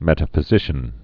(mĕtə-fĭ-zĭshən)